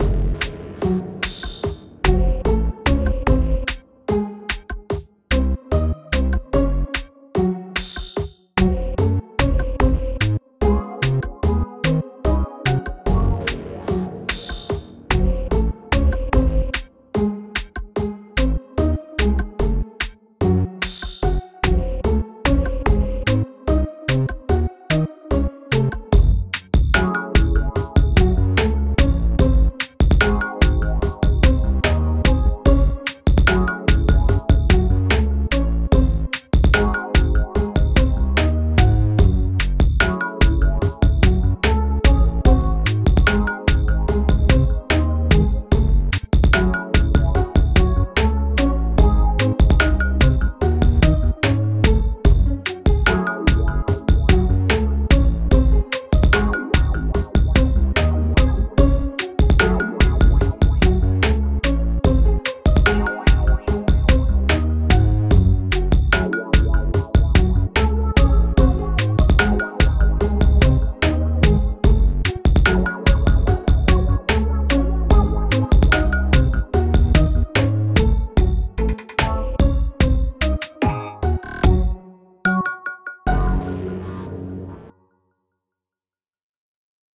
a thing in F sharp miner with sevenths (both major and miner)featuring drums, bass, pizzicato strings, and a harpsichord through a flanger and an LFO-filter chorus. Near the end, the LFO on the filter increases in speed, as if something bad will happen soon.